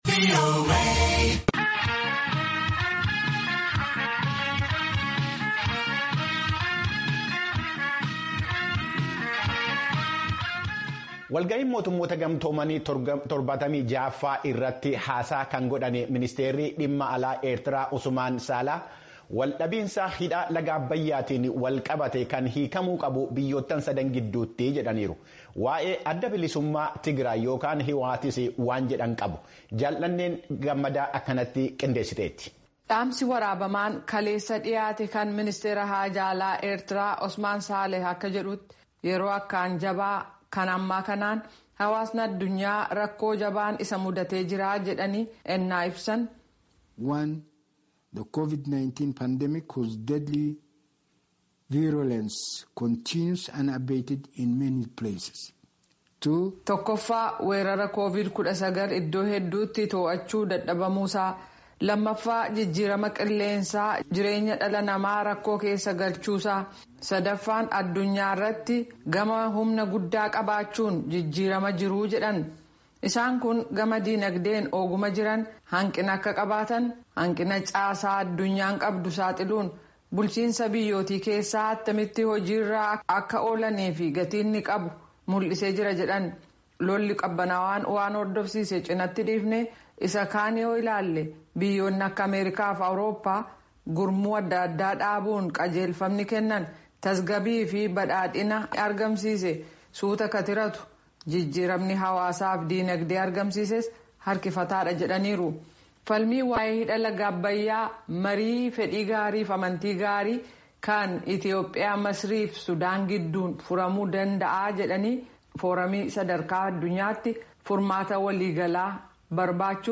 Sagalee dursee waraabamuun kaleessa koran waggaa kan Tokkummaa Mootummootaa irratti dhiyaate kanaan addunyaan yeroo ammaa rakoo gurguddaan qabamtee akka jiru kan ibsan ministriin dantaa alaa Ertraa Osmaan Saalee.
Gabaasa Guutuu Caqasaa